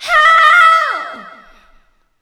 SCREAM3   -L.wav